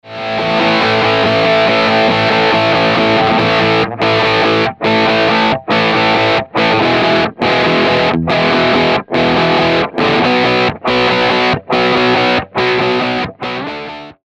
GR5 Preset – WALK (Drive)
Walk-crunch.mp3